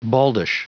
Prononciation du mot baldish en anglais (fichier audio)
Prononciation du mot : baldish